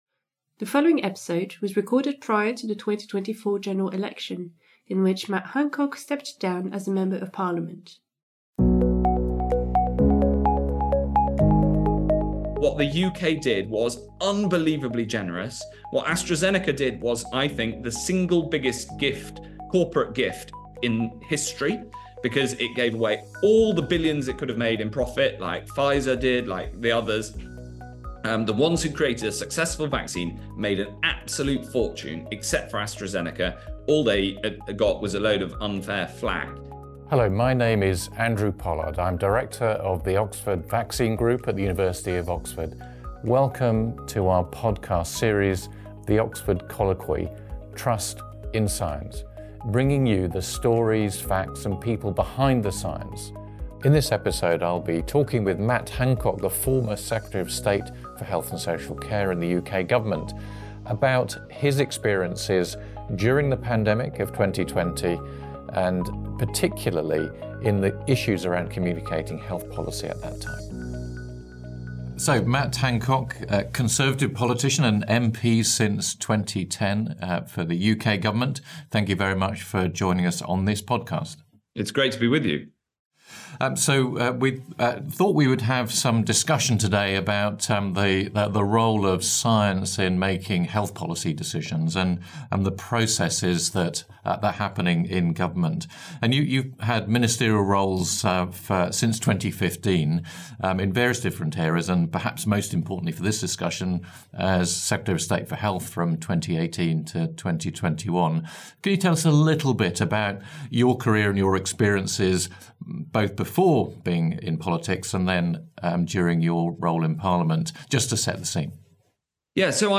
This interview was recorded prior to the 2024 General Election in which Matt Hancock stepped down as a member of parliament.